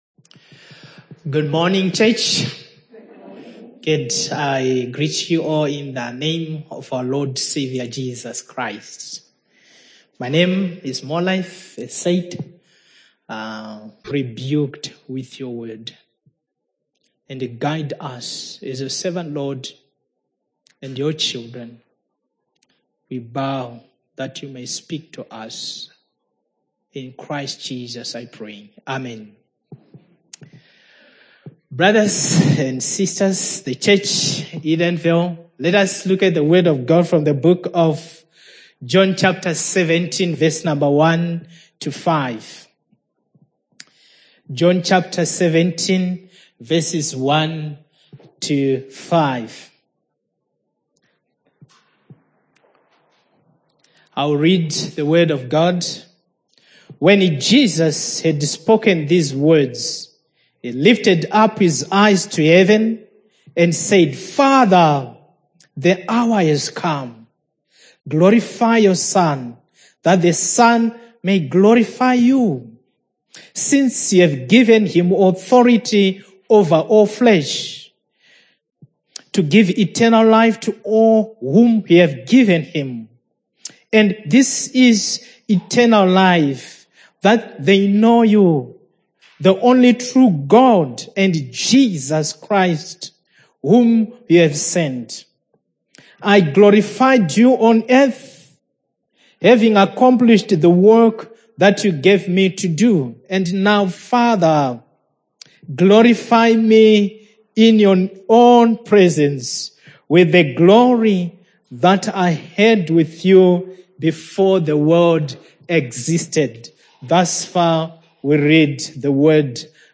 Audio Prayer